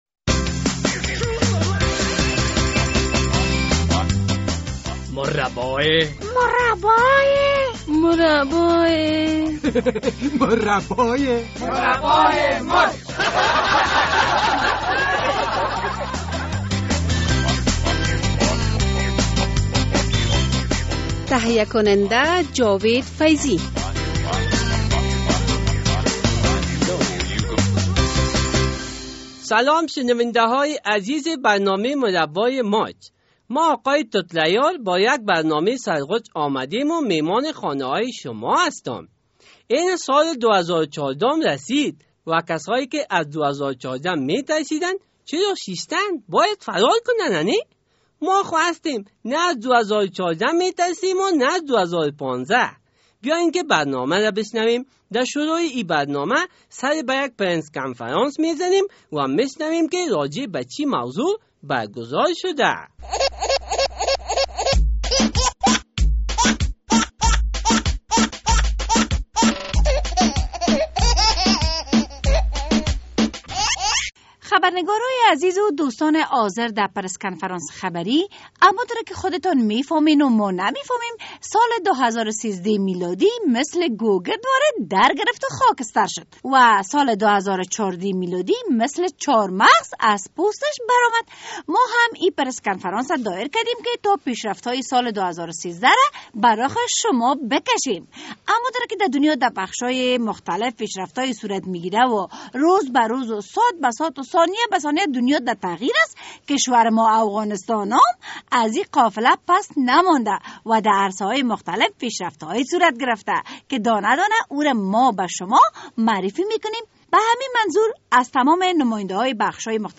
مصاحبه با رییس مبارزه با فساد و رشوه